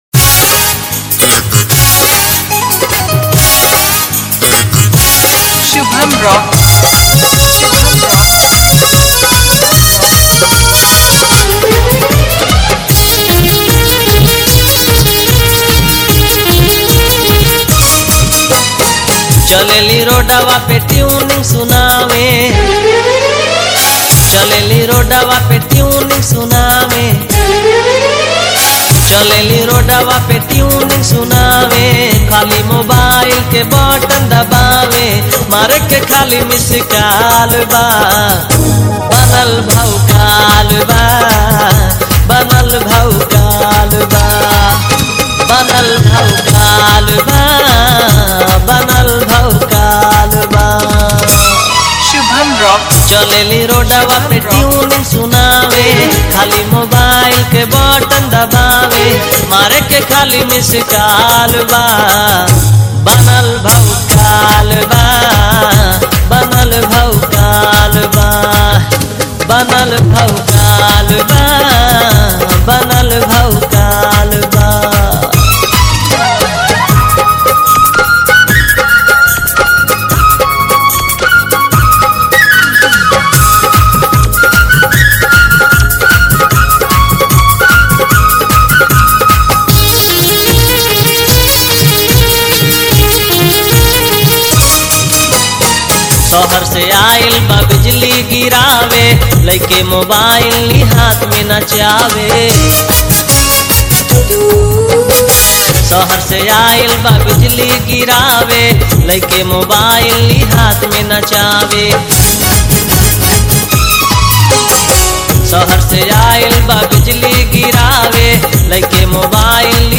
Mela Competition Filters Song